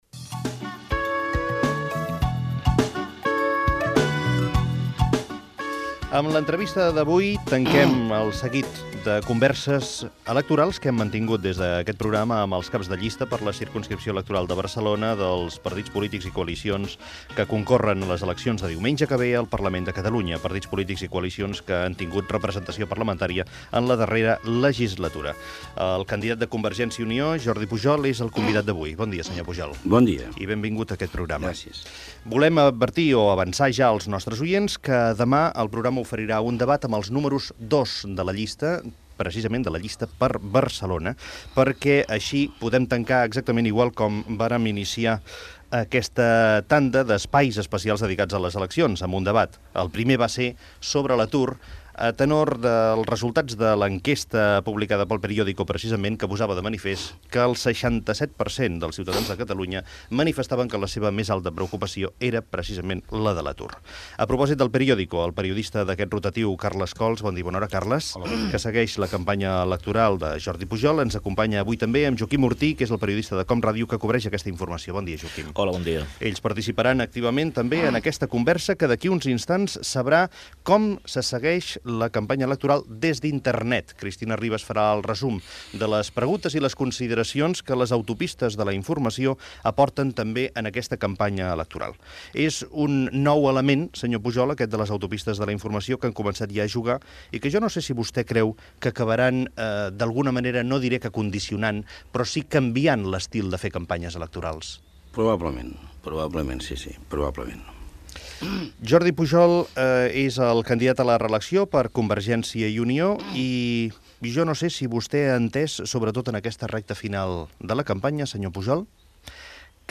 Fragment d'una entrevista al candidat a la reelecció a la presidència de la Generalitat, Jordi Pujol.
Info-entreteniment